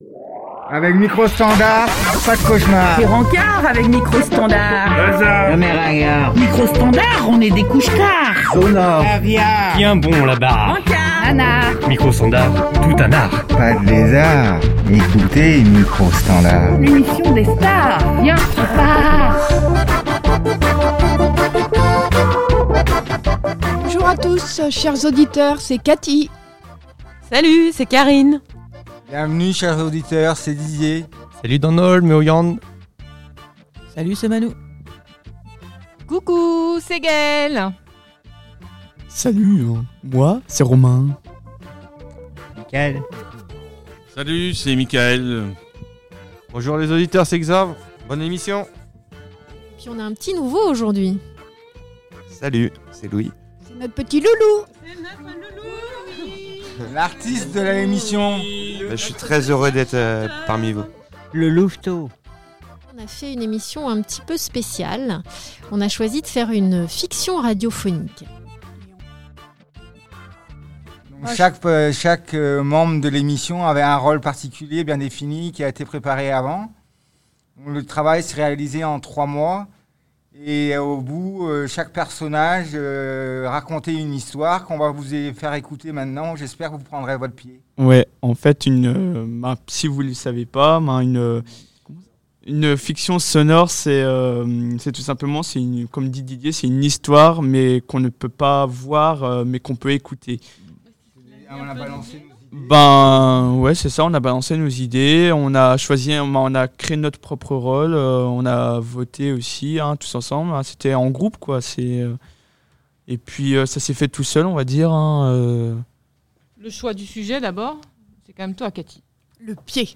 Nous nous sommes essayés à une forme radiophonique inhabituelle pour nous : la fiction radio. Nous avons joué, improvisé pour vous embarquer avec...